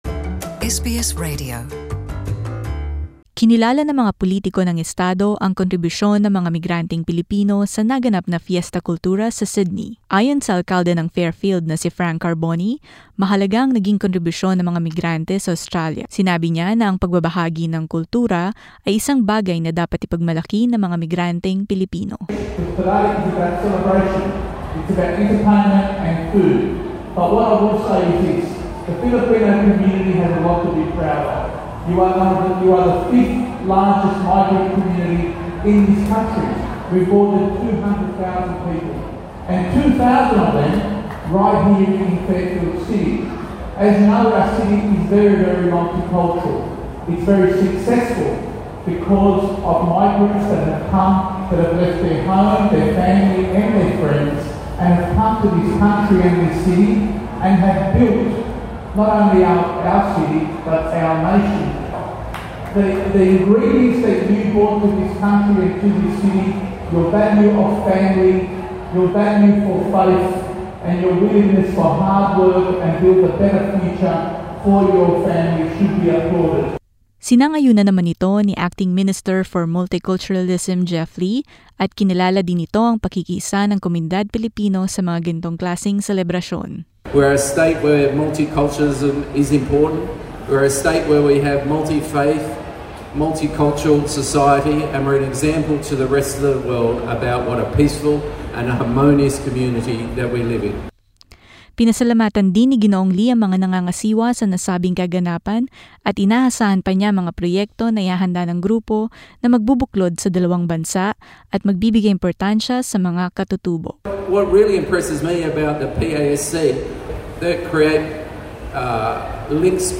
Dignitaries graced the official opening of the annual Fiesta Kultura event in Sydney.